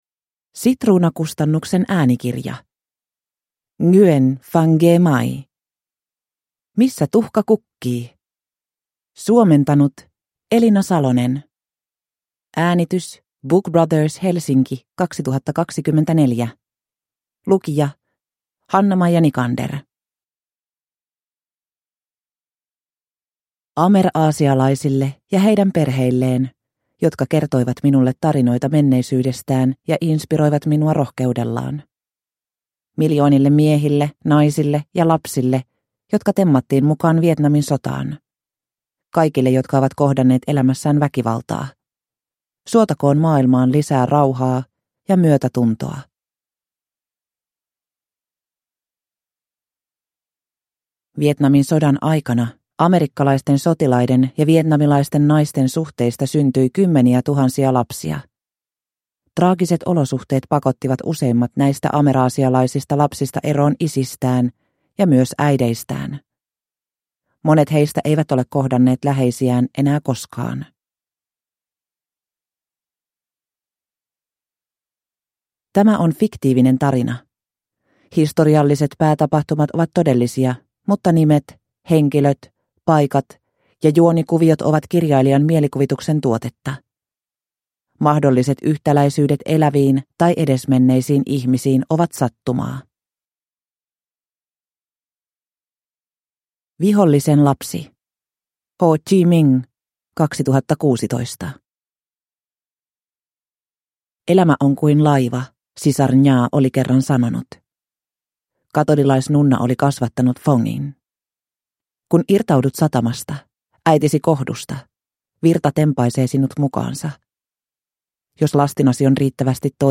Missä tuhka kukkii – Ljudbok